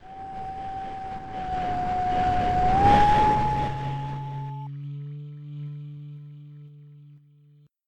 moan1.ogg